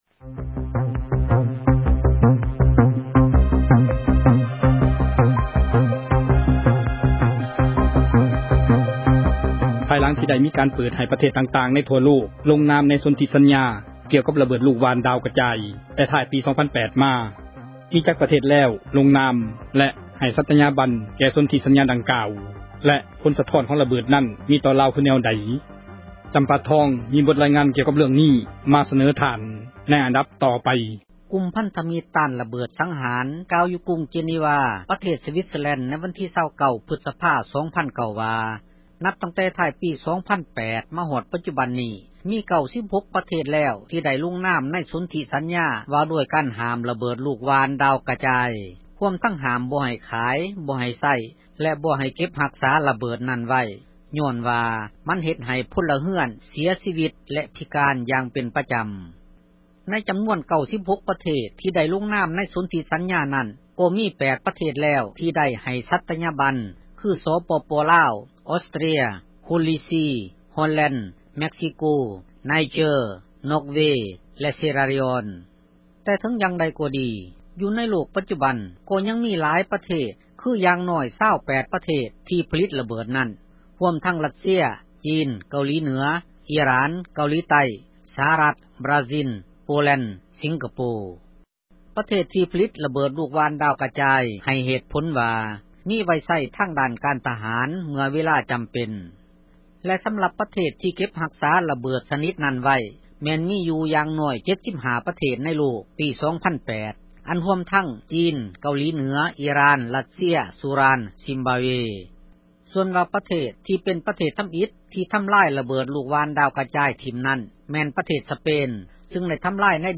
ມີບົດຣາຍງານ ກ່ຽວກັບເຣື້ອງນີ້ ມາສເນີທ່ານ.